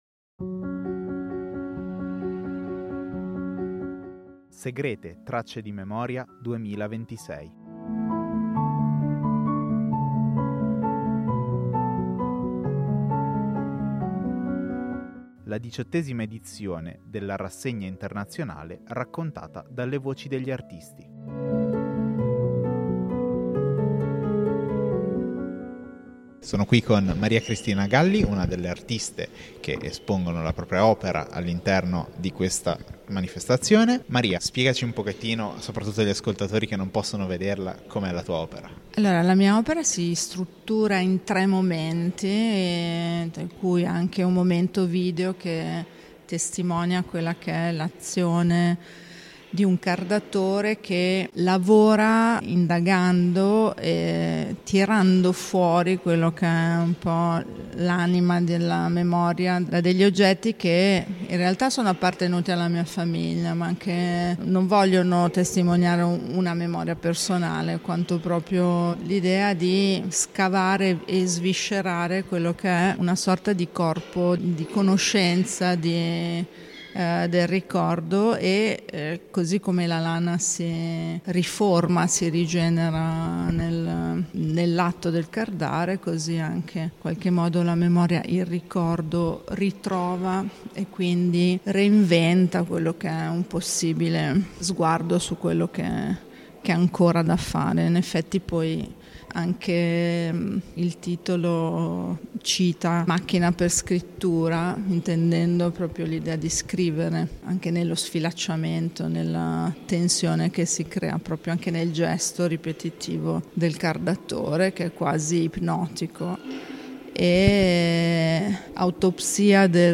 Intevista